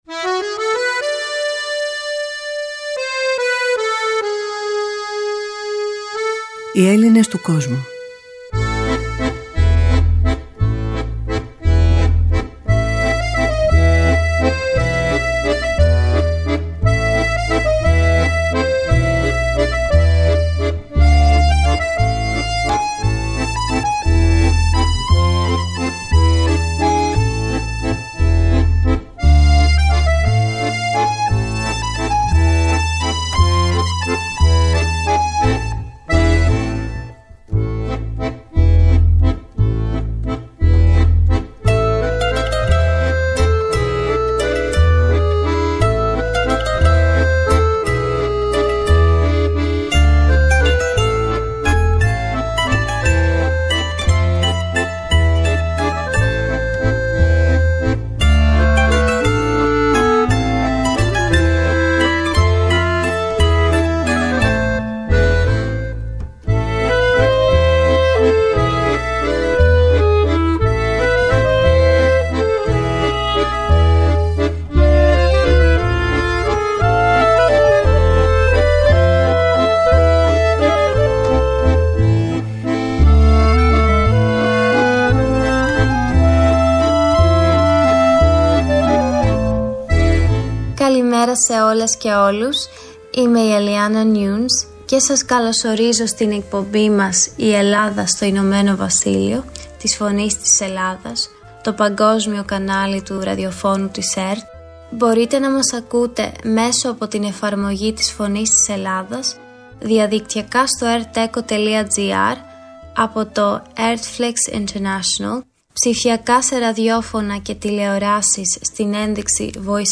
Στην εκπομπή, ο καλεσμένος θα μιλήσει για την πολυδιάστατη ελληνική κοινότητα στο Ηνωμένο Βασίλειο και θα μοιραστεί τις απόψεις του για σημαντικά ζητήματα, όπως το φαινόμενο του brain drain και η επιστροφή των Γλυπτών του Παρθενώνα.